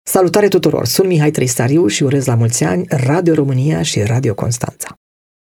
De Ziua Radioului Public, de la înființarea căruia au trecut 97 de ani, personalitățile Constanței transmit mesajelor lor de felicitare pentru Radio România.
Interpretul și compozitorul Mihai Trăistariu: